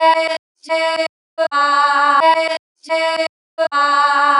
• chopped vocals 109-127 female 1 (9) - Em - 127.wav